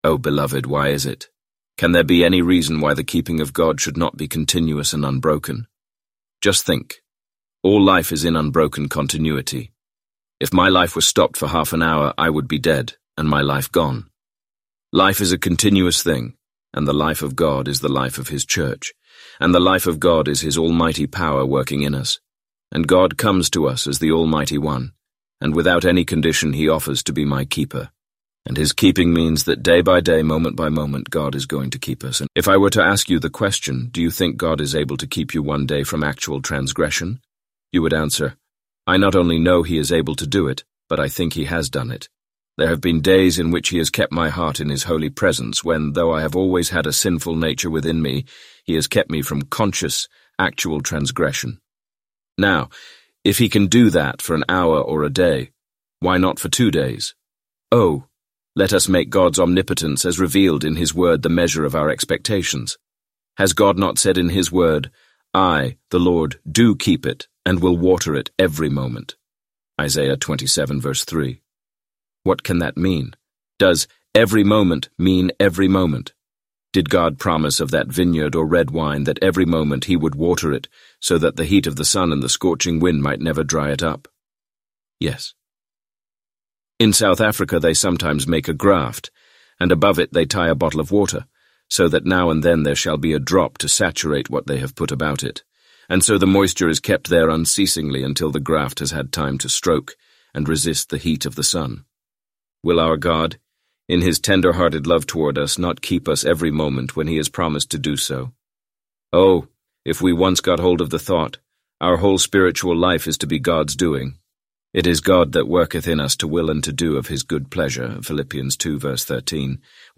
Complete Audiobook Download MP3 Show individual sections (2) Section 1 Section 2